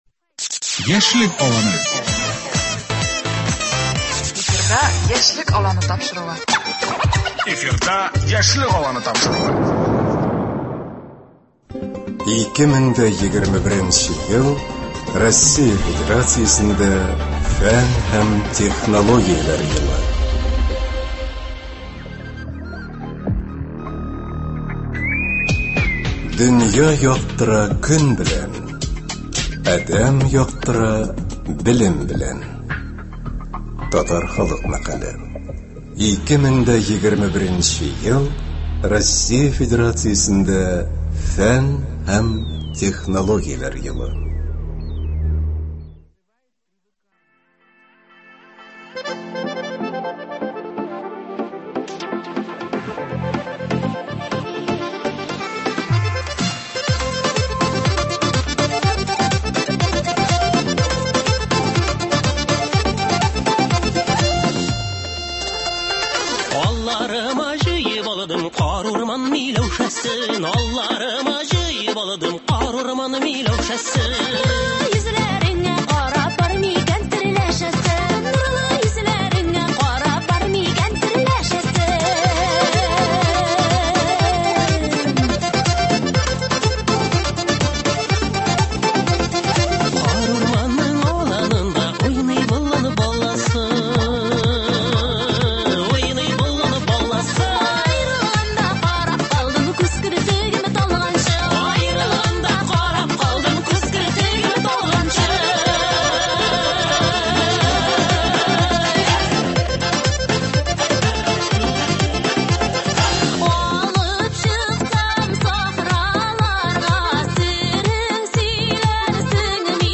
Шушы ике тематиканы берләштереп, Татарстан радиосы бүген игътибарыгызга яңа тапшырулар циклын тәкъдим итә. Бу тапшыруларда сезне яшь галимнәр, аспирантлар һәм фән белән кызыксынучы яшьләр белән очрашулар көтә.